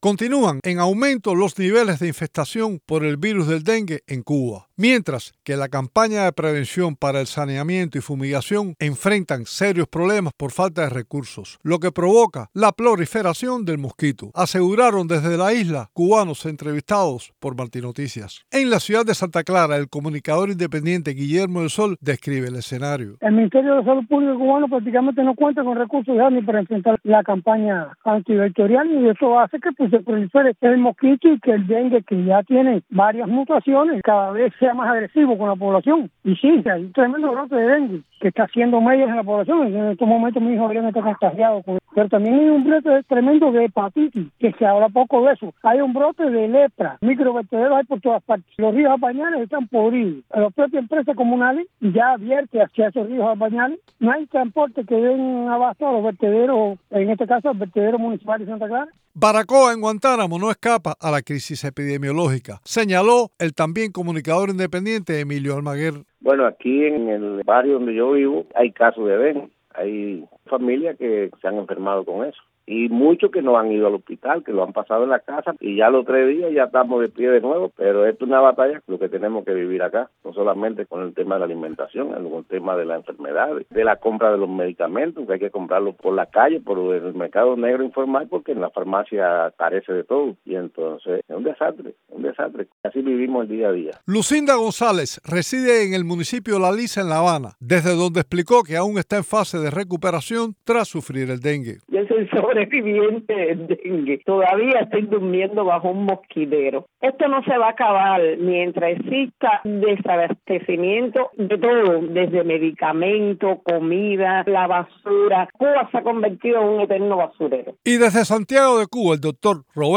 Desde la isla, varios cubanos entrevistados por Martí Noticias, alertaron sobre la proliferación de los focos del mosquito Aedes Aegypti, transmisor del dengue y advirtieron que los niveles de infestación por el virus van en aumento.